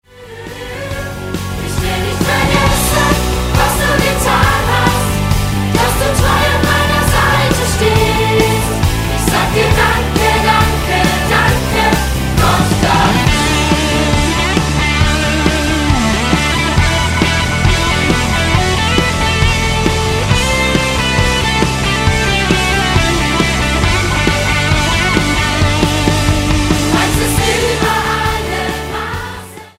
Musical-CD
14 Lieder und kurze Theaterszenen